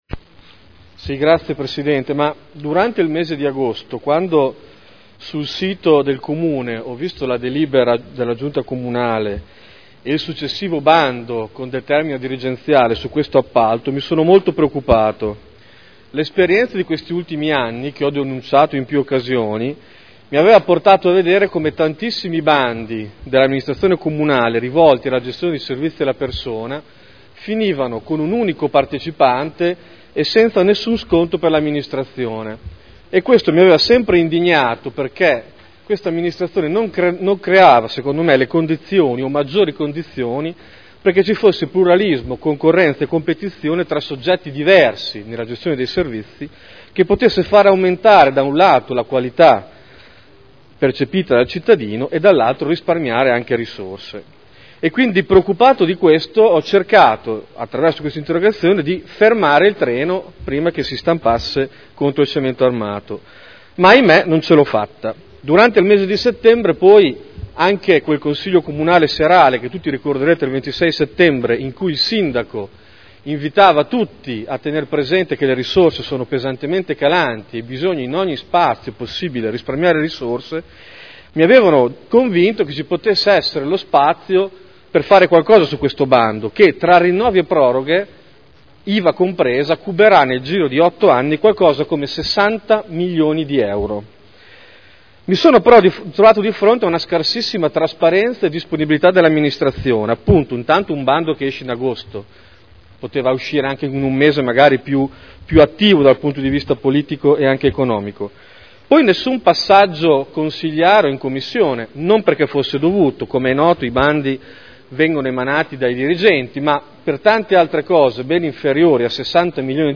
Davide Torrini — Sito Audio Consiglio Comunale